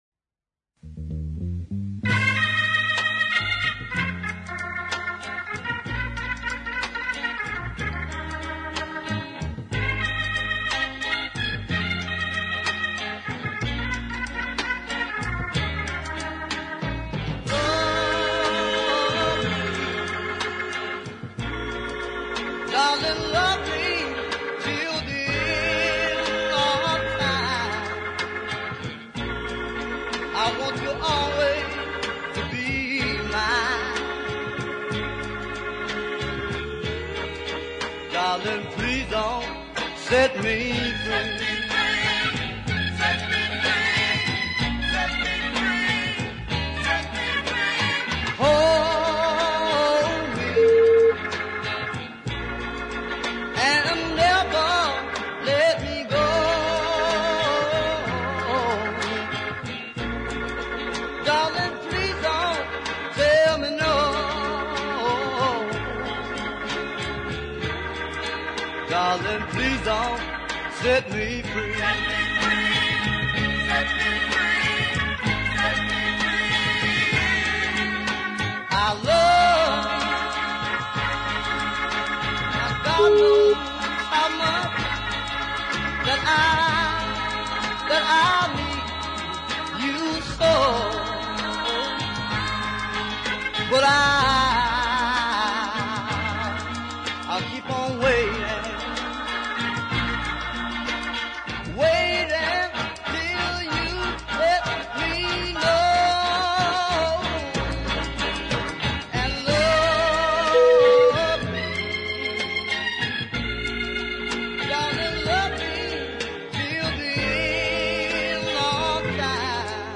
strong ballad side
a melodic R & B slowie